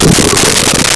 Belch
belch.wav